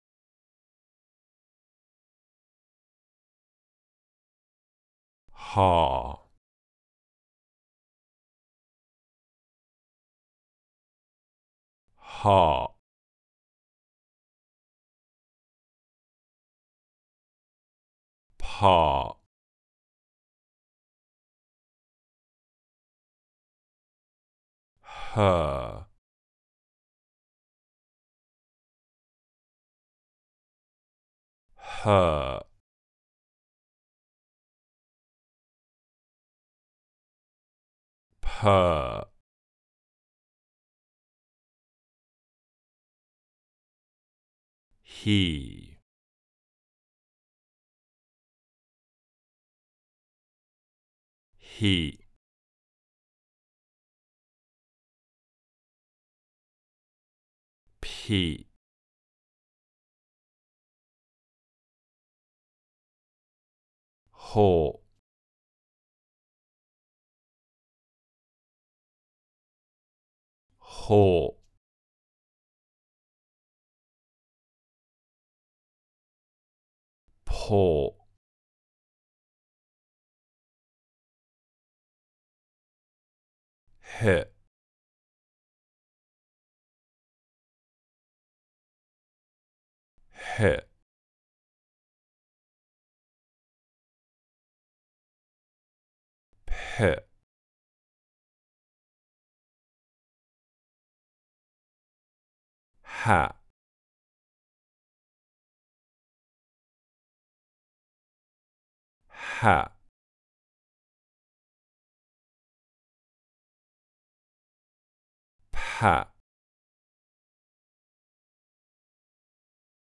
The Basic Building Blocks of Speech - Aspiration - British English Pronunciation RP Online Courses
Aspirated /p/ + stop
04_aspiration_pstop.mp3